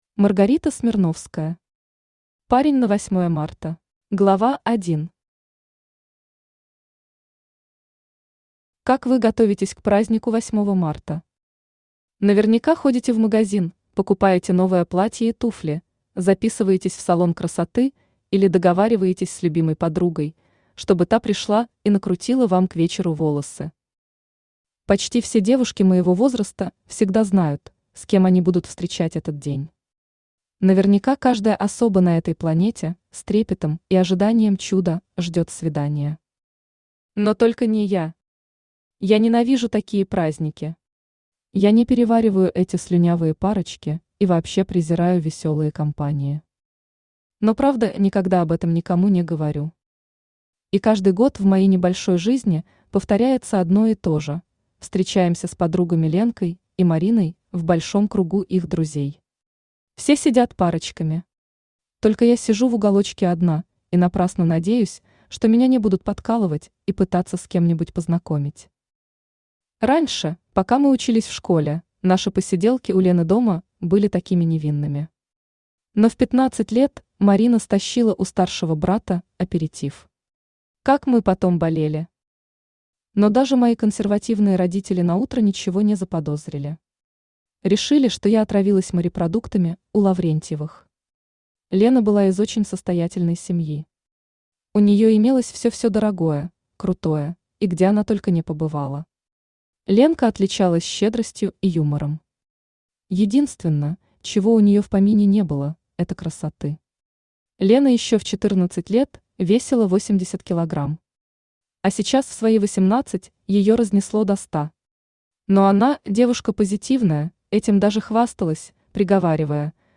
Аудиокнига Парень на 8 марта | Библиотека аудиокниг
Aудиокнига Парень на 8 марта Автор Маргарита Смирновская Читает аудиокнигу Авточтец ЛитРес.